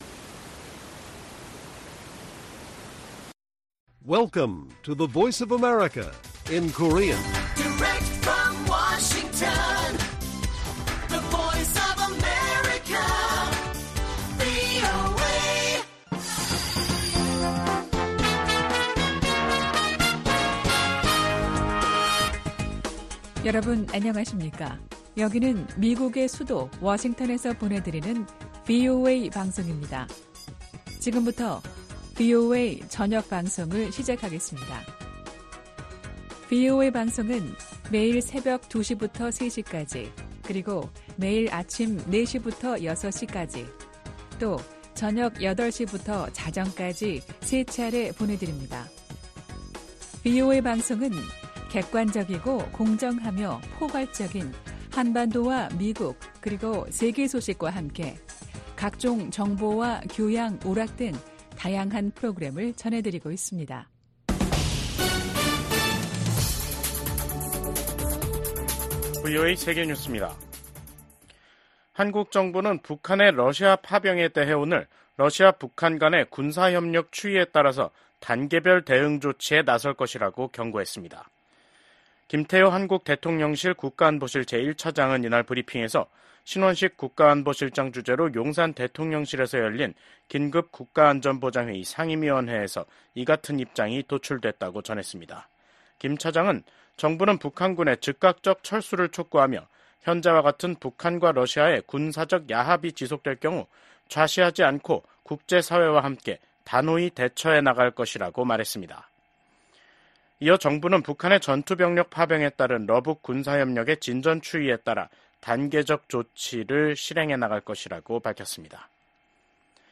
VOA 한국어 간판 뉴스 프로그램 '뉴스 투데이', 2024년 10월 22일 1부 방송입니다. 미국 정부가 북한의 러시아 파병은 러시아 대통령의 절박함과 고림감이 커지고 있다는 증거라고 지적했습니다. 군축과 국제안보 문제를 다루는 유엔 총회 제1위원회 회의에서 북한의 핵∙미사일 프로그램 개발에 대한 우려와 규탄이 연일 제기되고 있습니다.